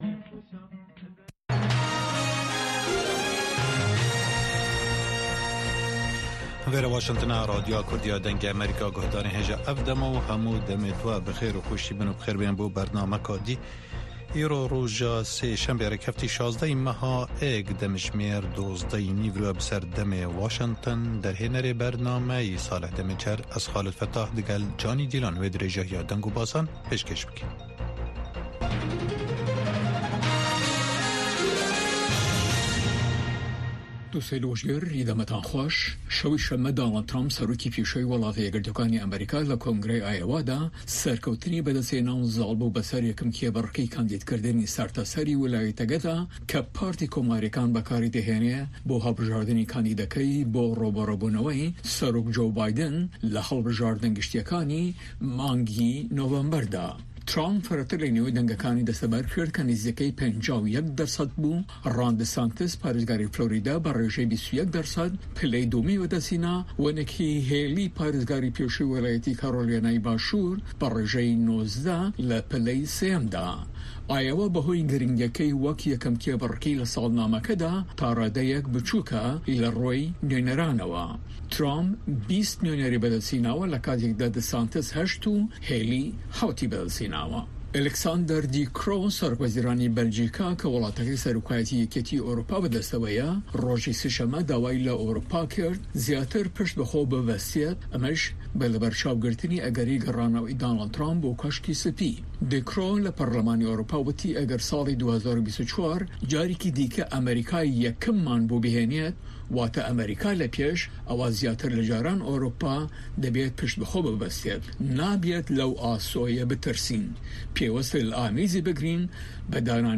Nûçeyên Cîhanê ji Dengê Amerîka